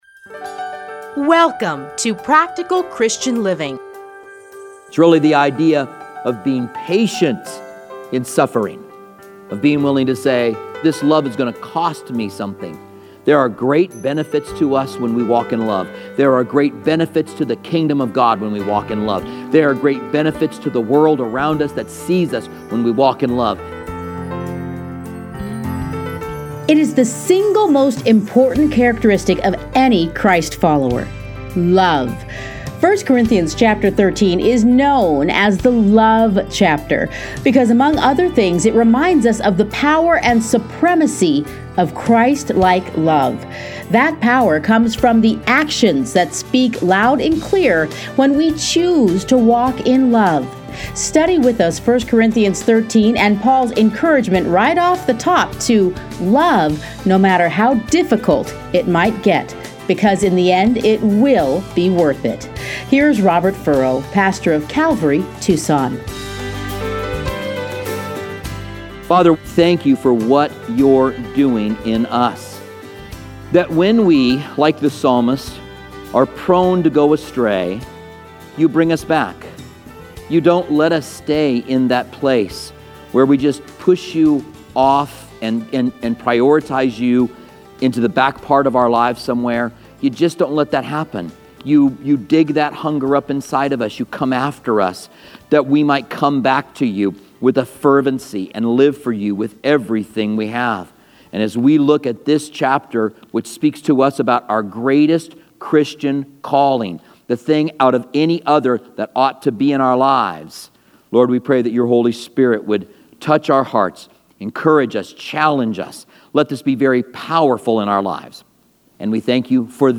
Listen here to a teaching from 1 Corinthians.